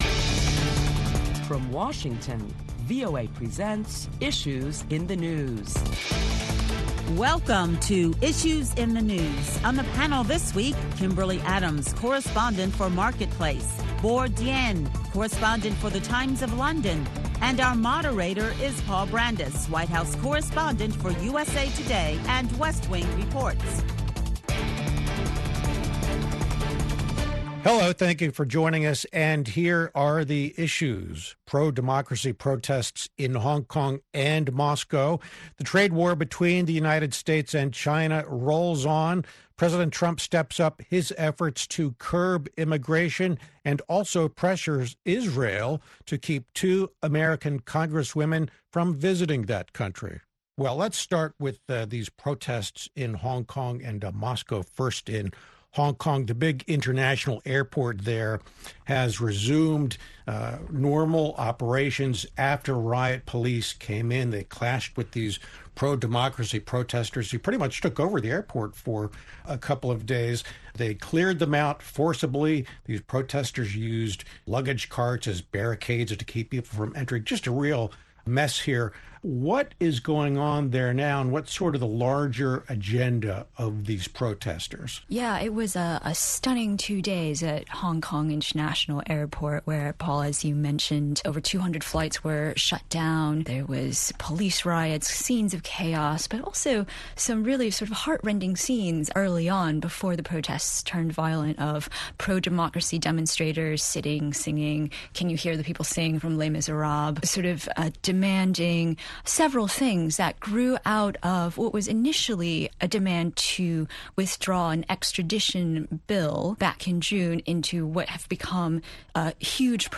Prominent Washington correspondents discuss topics making headlines around the world.